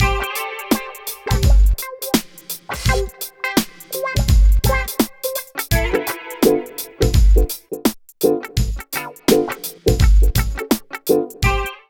137 LOOP  -L.wav